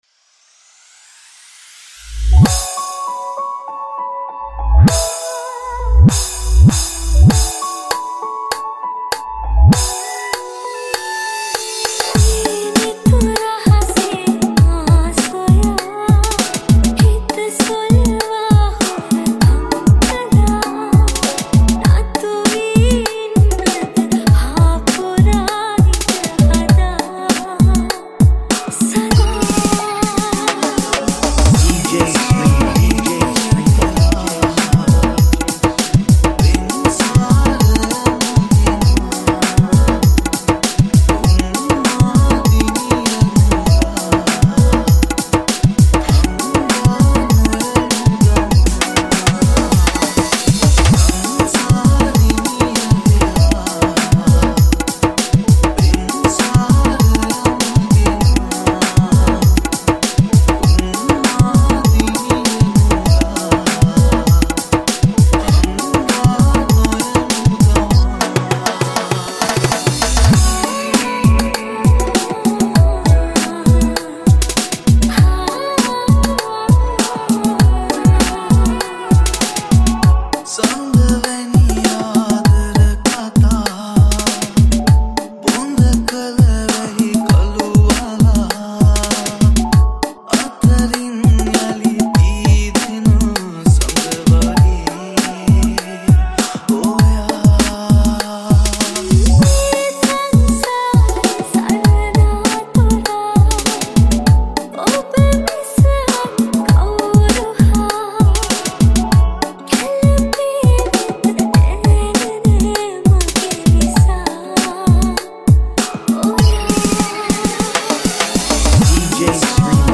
(Thabla & Congo)Mix